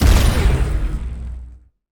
weapon_blaster_003.wav